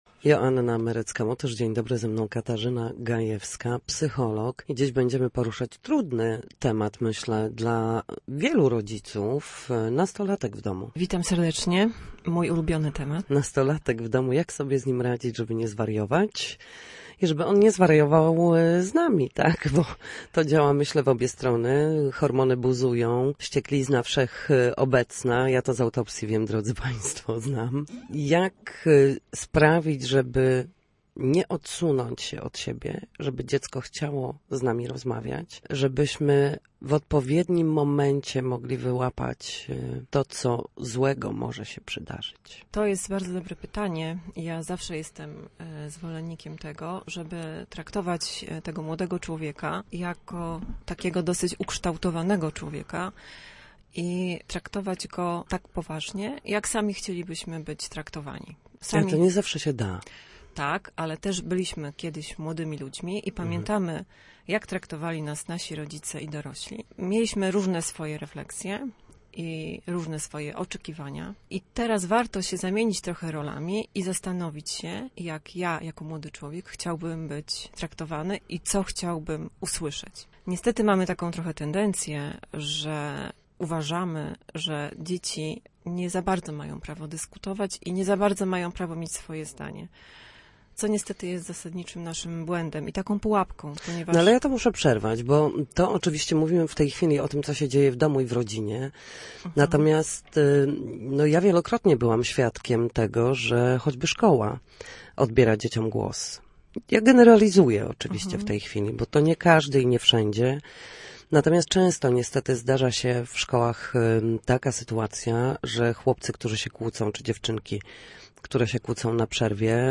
W każdą środę, w popołudniowym Studiu Słupsk Radia Gdańsk, dyskutujemy o tym, jak wrócić do formy po chorobach i urazach.